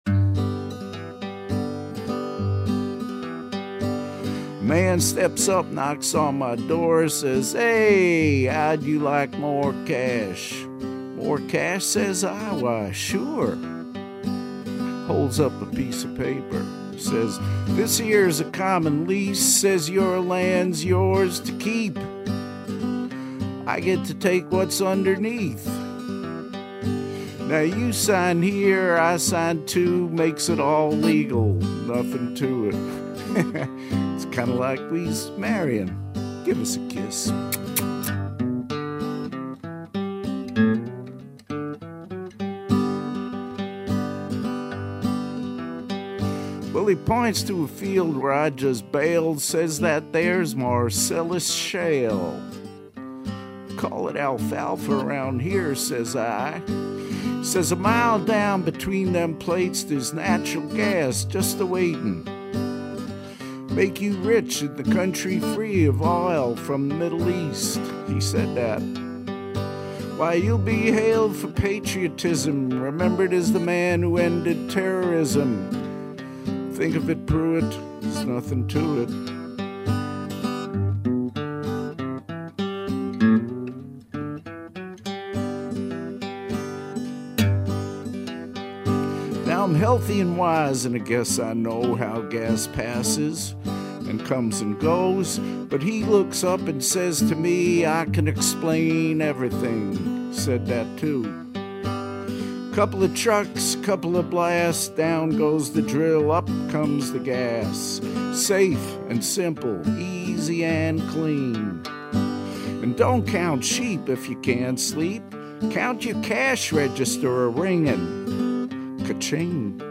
Articles Fall 2012 Talkin' Frackin' Blues lyric